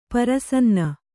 ♪ parasanna